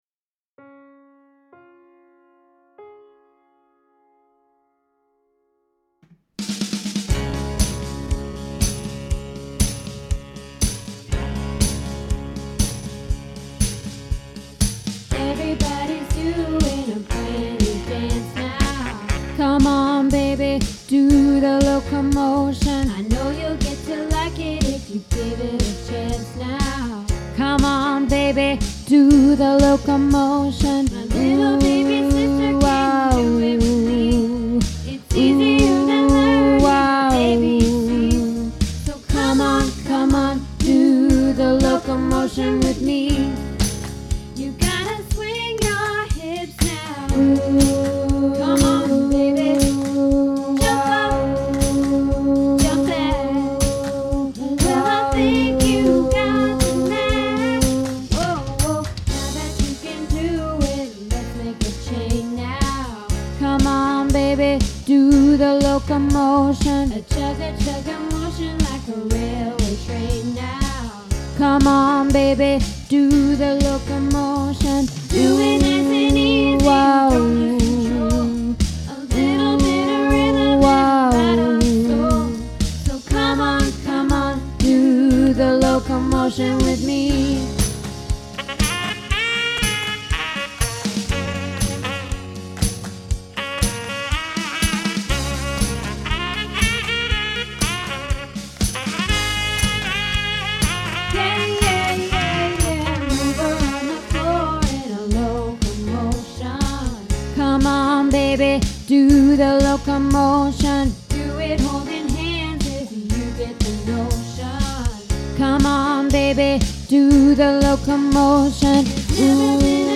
Locomotion - Bass